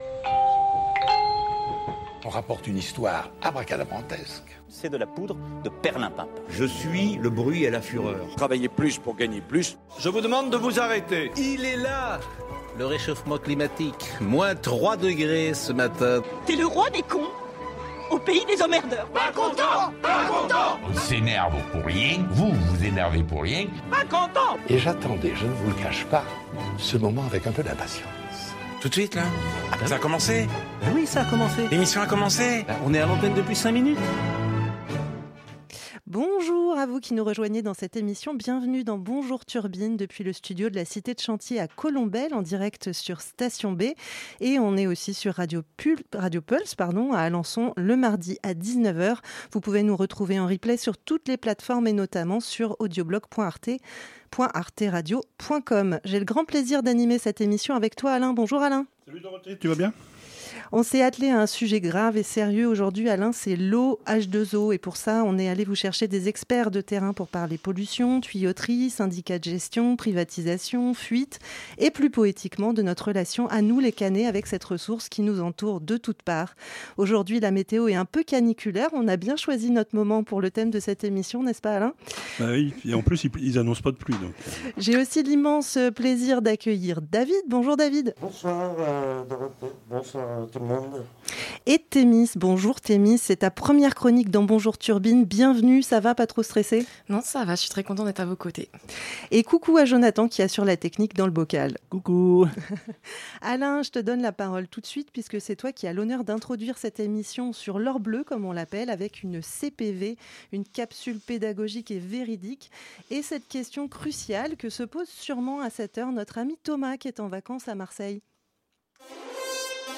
Radio Pulse 90.0FM à Alençon